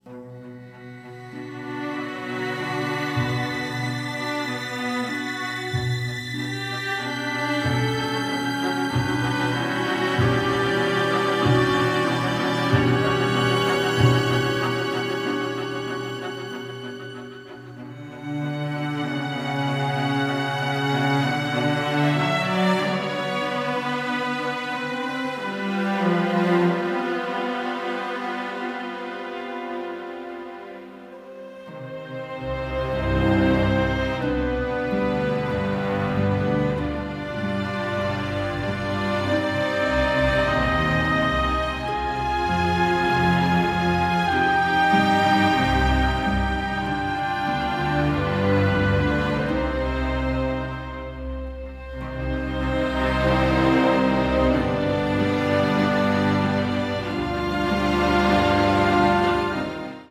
intense, emotional and moving large-orchestral scores
The music was recorded at Air Studios, London